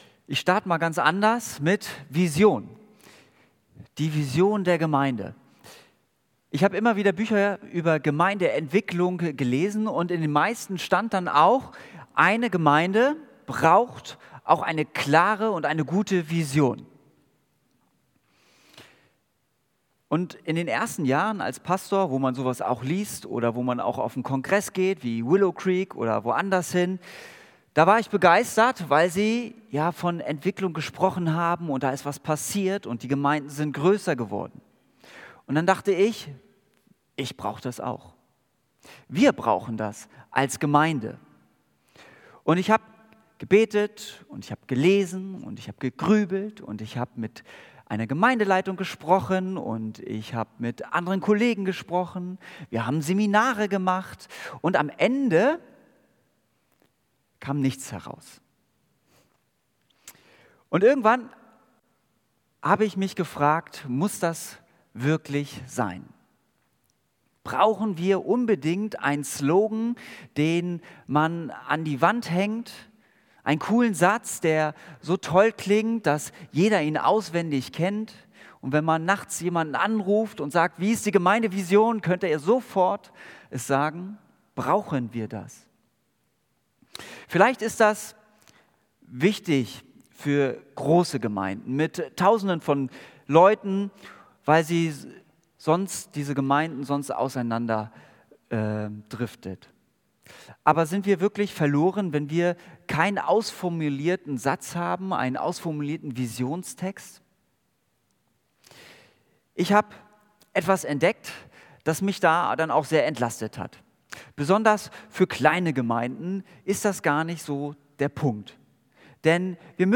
Predigt Geht.